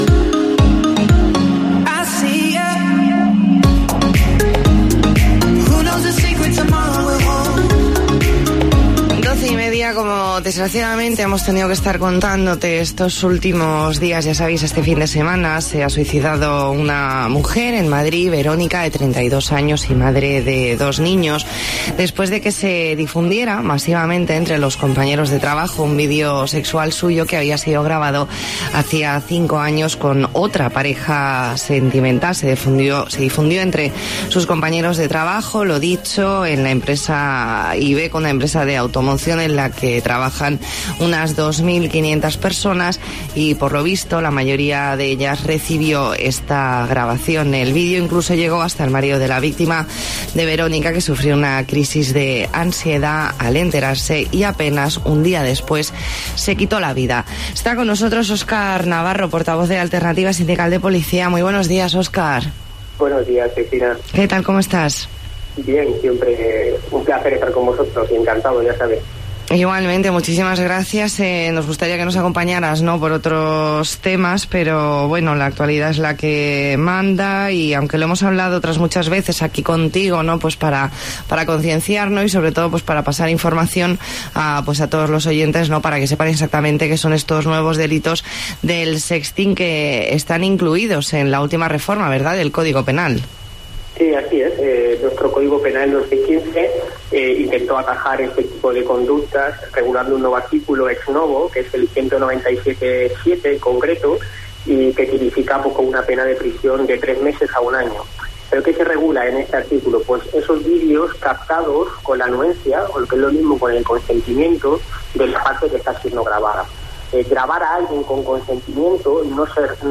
Entrevista en La Mañana en COPE Más Mallorca, jueves 30 de mayo de 2019.